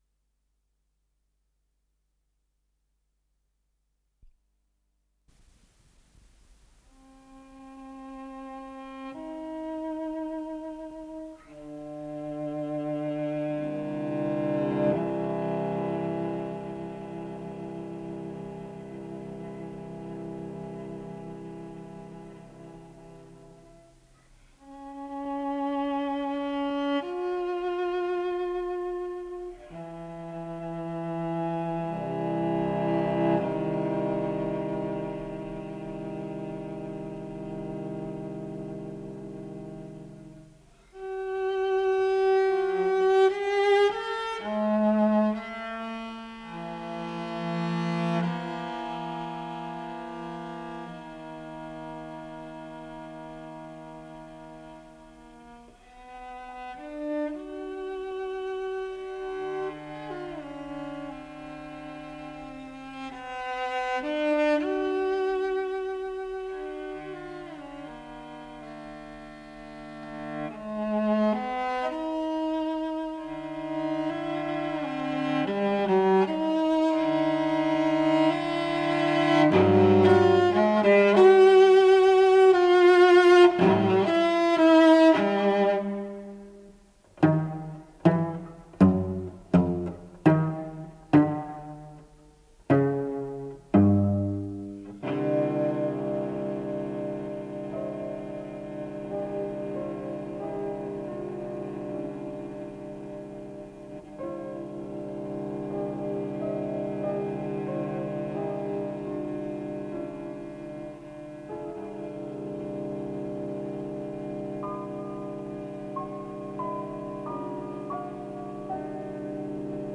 виолончель Владимир Крайнев - фортепиано